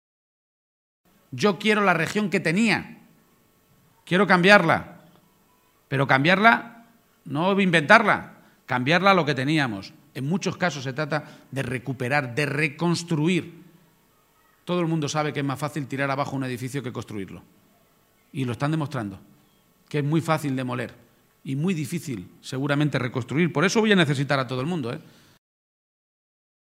Audio Page en Mondejar-3